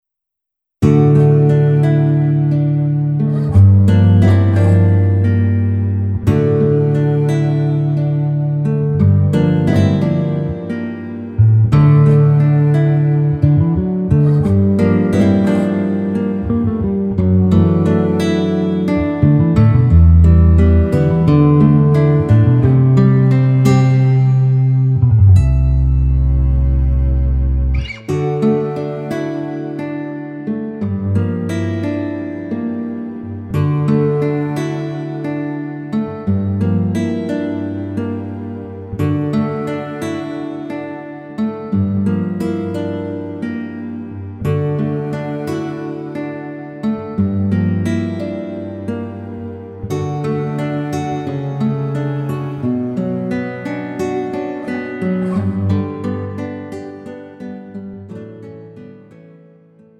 음정 (-1키)
장르 가요 구분 Pro MR